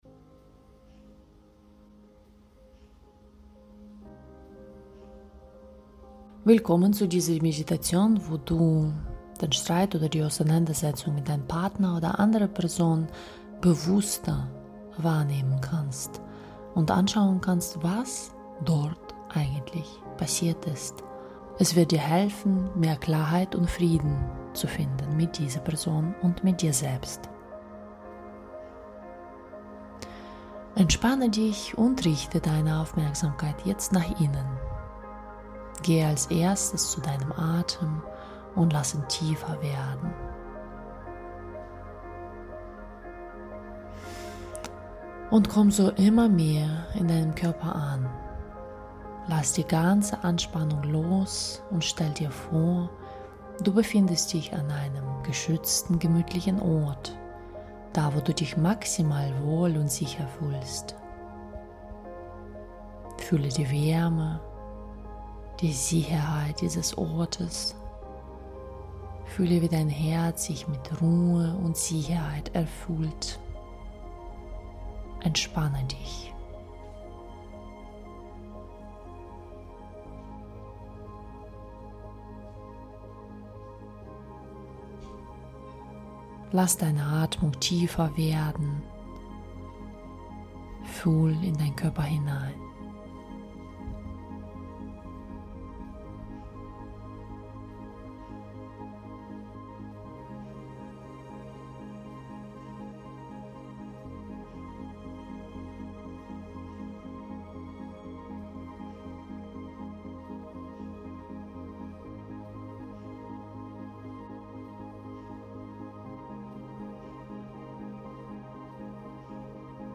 Partnerschaftsmeditation.mp3